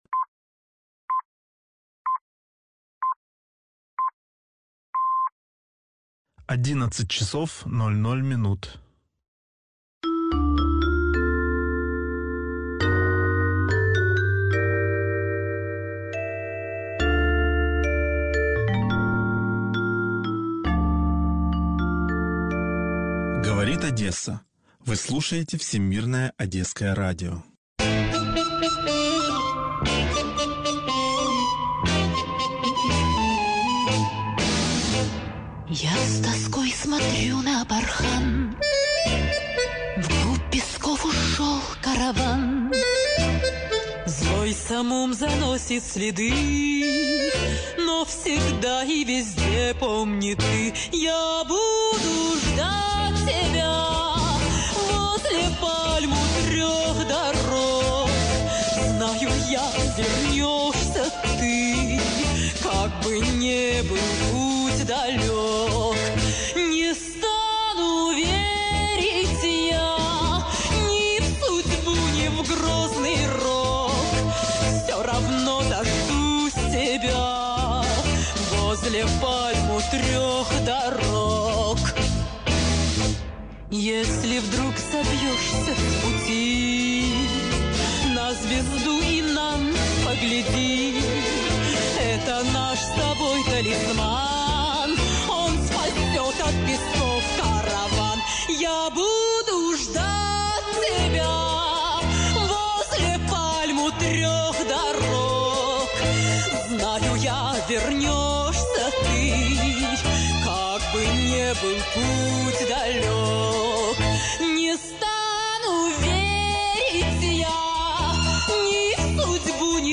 В приложенном аудио, как раз позывные - из этой оперетты.